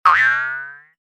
bounce_boing_32